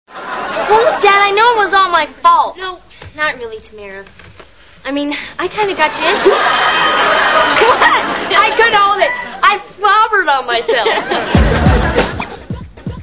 Bloopers.
Tamera slobbers on herself causing everyone to crack up!   51 Kb